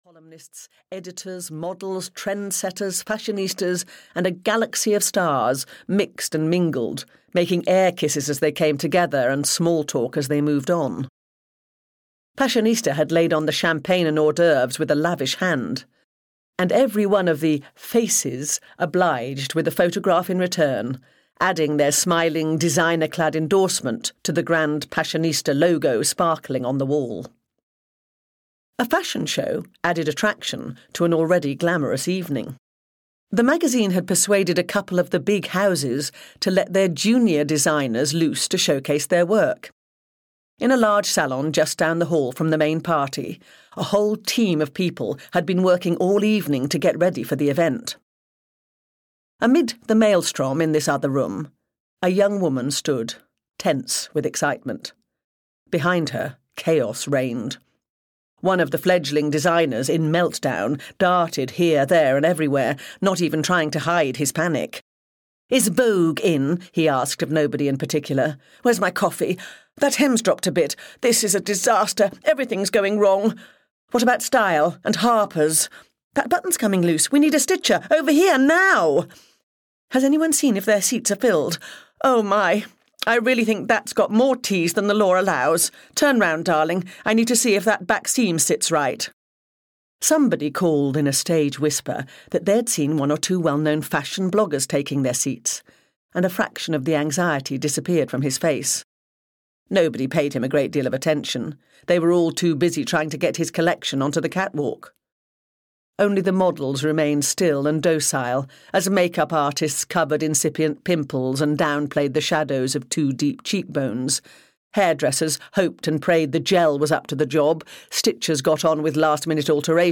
Dead Gorgeous (EN) audiokniha
Ukázka z knihy